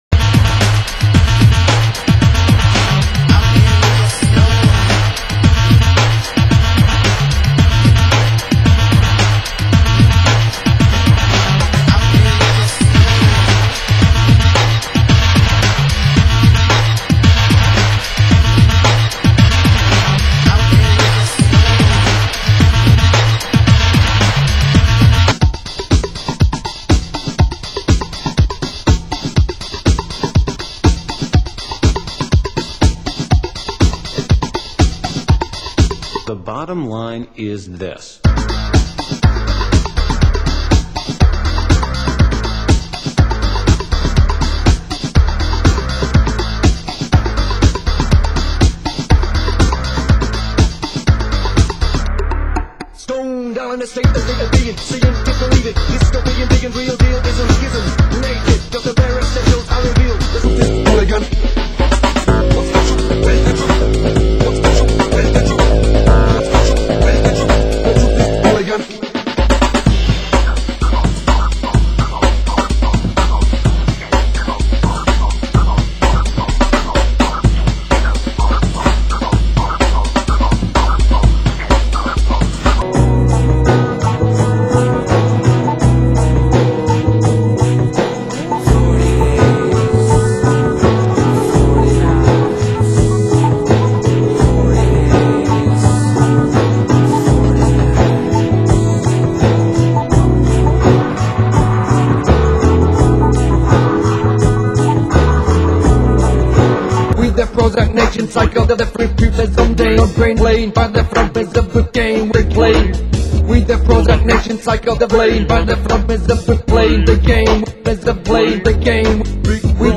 Genre: Break Beat